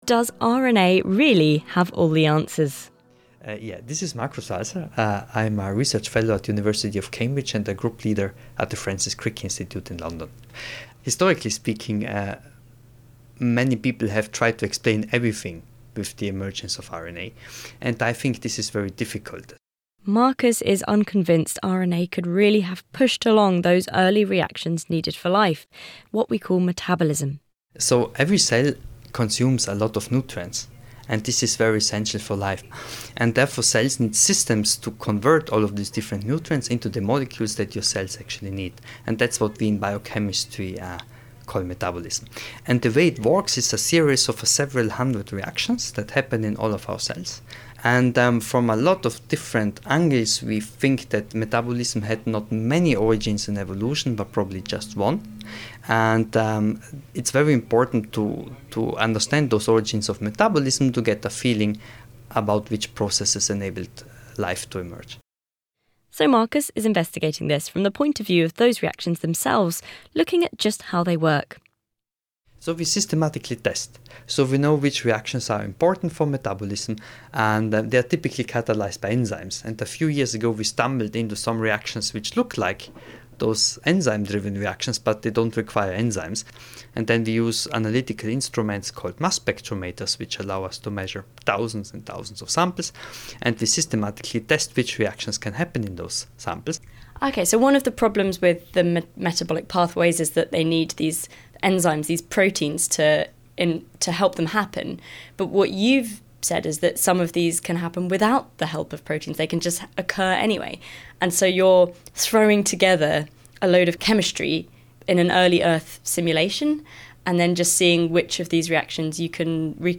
Interviews with Scientists